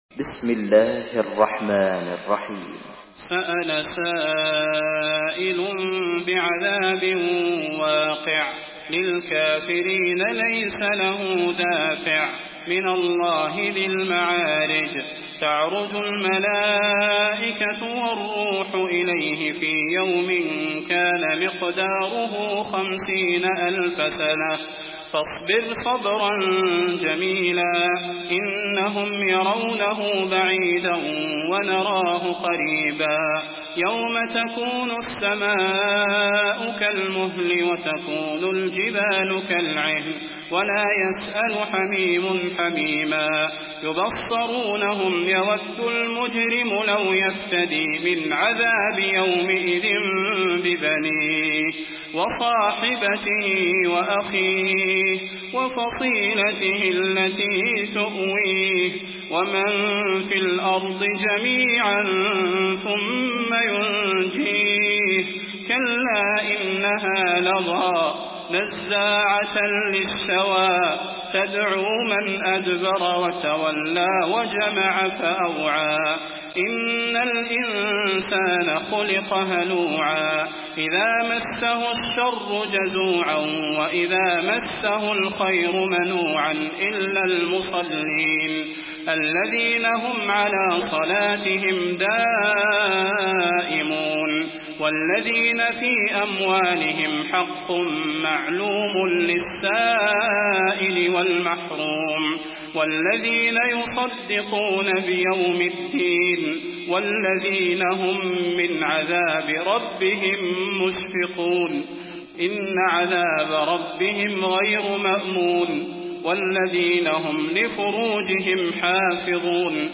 Surah Al-Maarij MP3 by Salah Al Budair in Hafs An Asim narration.
Murattal Hafs An Asim